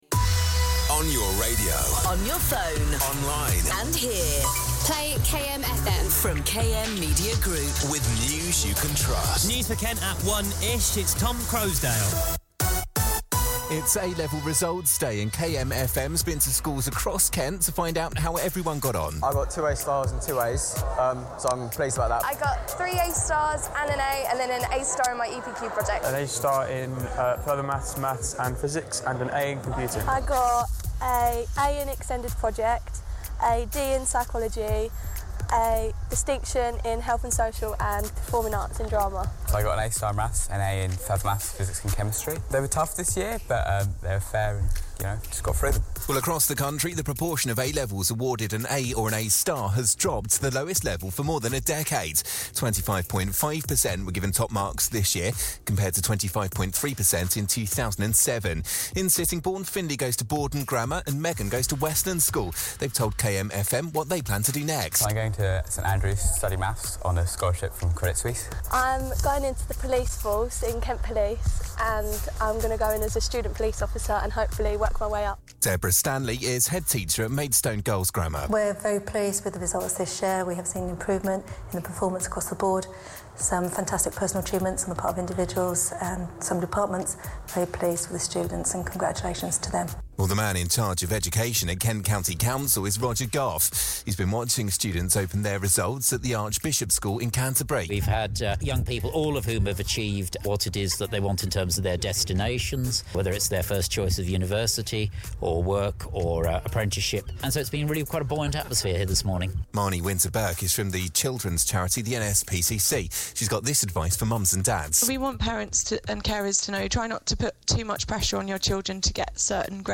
has your headlines for Kent on A level results day